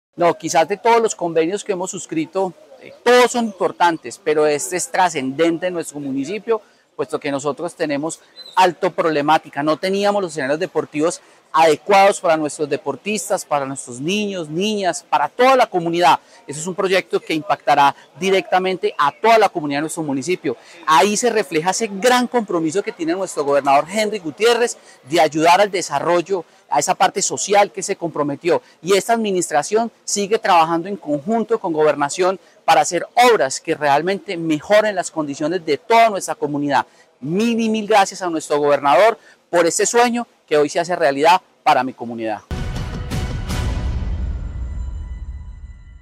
Fabio Andrés Ramírez, alcalde de Belalcázar